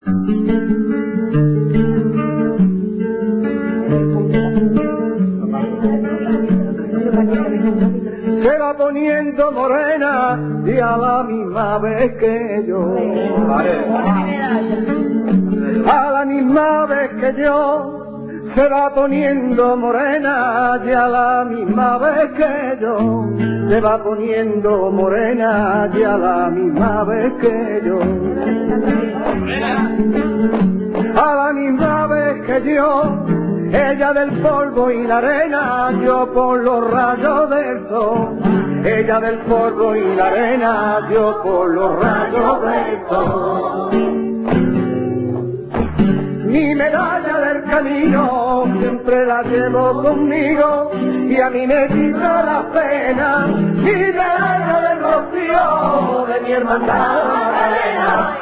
SEVILLANAS ROCIERAS